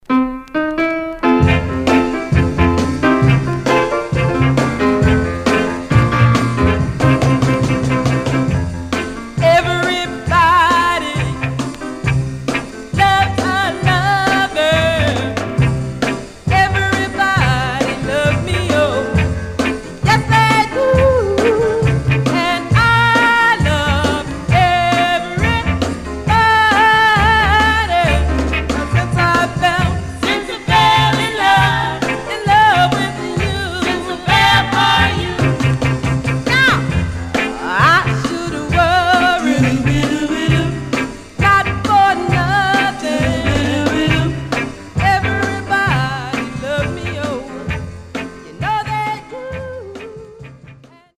Stereo/mono Mono
Girl Groups